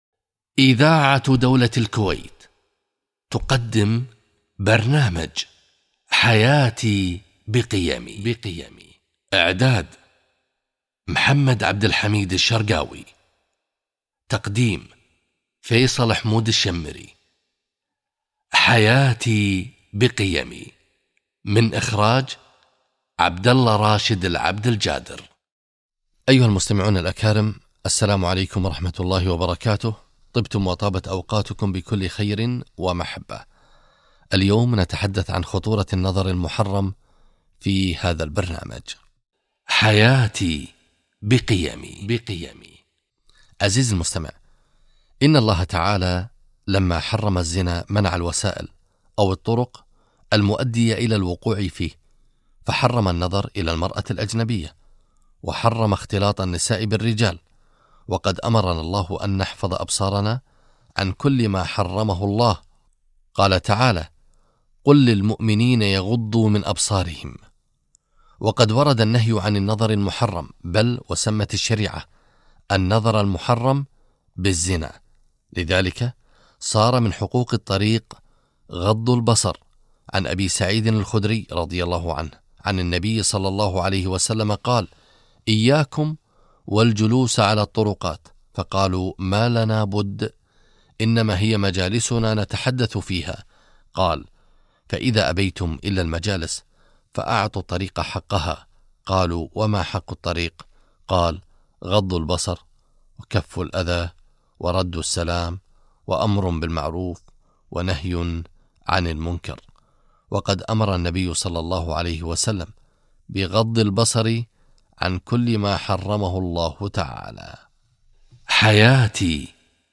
غض البصر - لقاء إذاعي في برنامج حياتي بقيمي